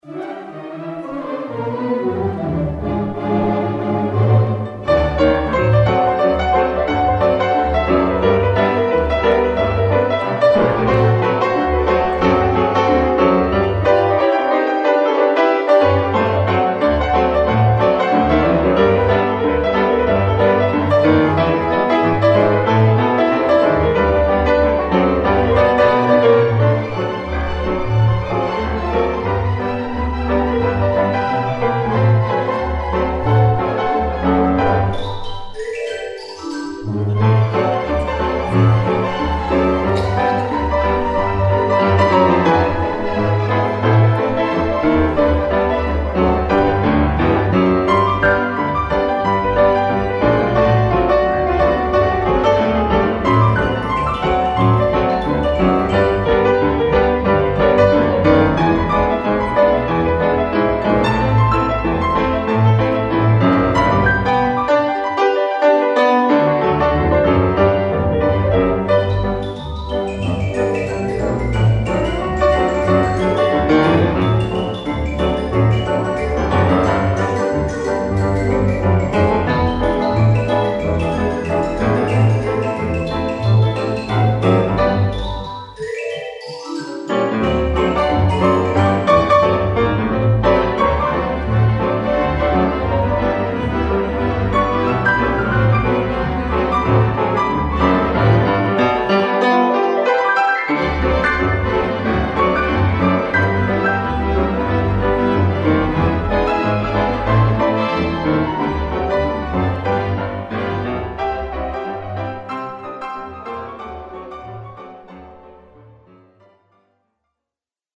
The end result of the data recovery was 8 tracks - each track had chunks of data from different mics.
I could not mix it - I just had to live with the levels as recorded.
Here's a short clip from that recording where they played ragtime on the Theater organ - a duet with the organ and piano.
Do you find a few odd moments here and there when some of the sounds coming out of that beast are terrifying, as well?
organ_ragtime.mp3